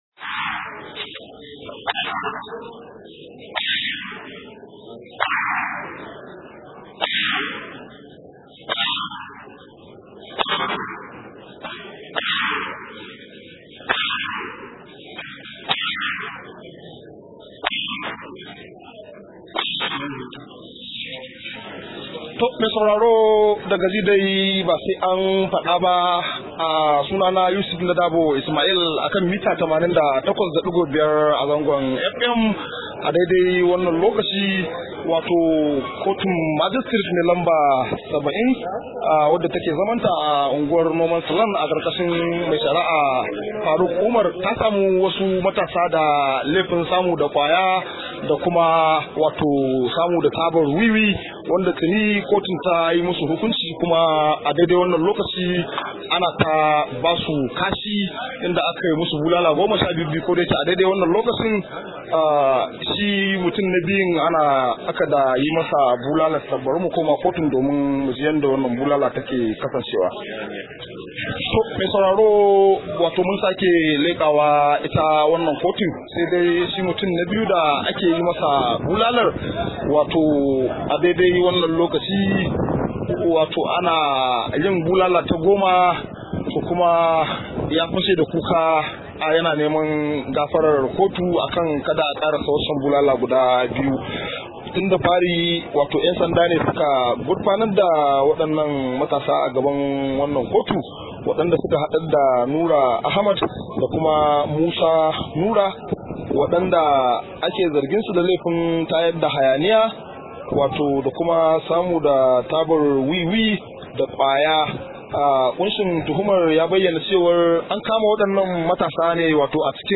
Rahoto: Domin mu tsare dukiyar al’umma shi ya sa mu ke shan ƙwaya – Mai Gadin Babur
Akwai cikakken rahoton a muryar da ke kasa.